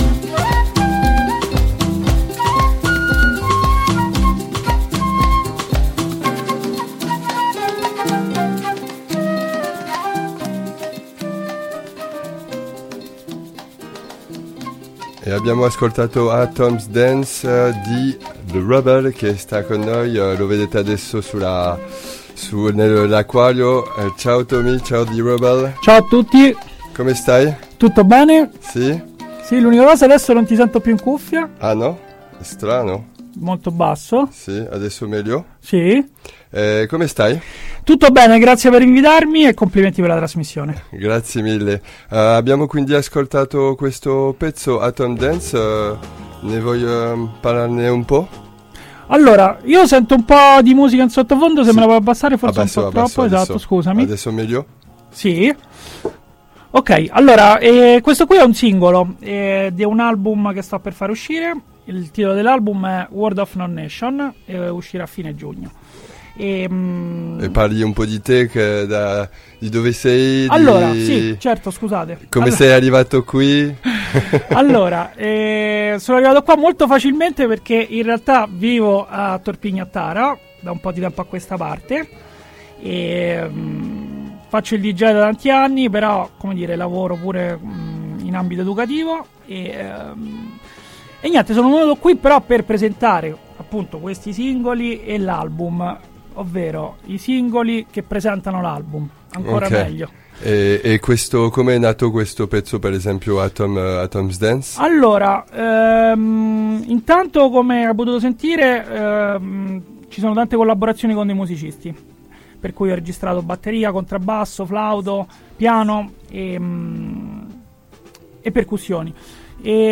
Oltre a un po’ di chiacchiere per ripercorrere genesi e collaborazioni dell’album, ascolteremo anche i singoli di lancio che già sono usciti in queste settimane.